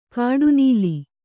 ♪ kāḍu nīli